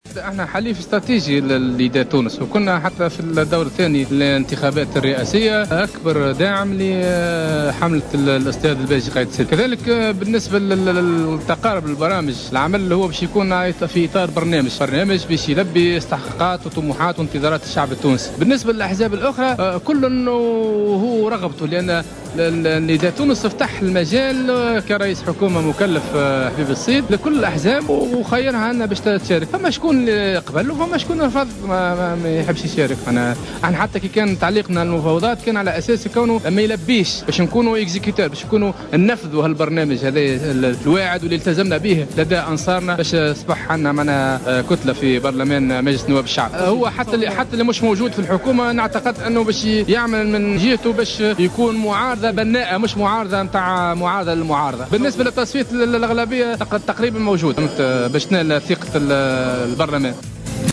أكد النائب عن حزب الاتحاد الوطني الحر يوسف الجويلي في تصريح ل"جوهرة أف ام" أن حكومة الحبيب الصيد الجديدة ستحصل على ثقة مجلس نواب الشعب.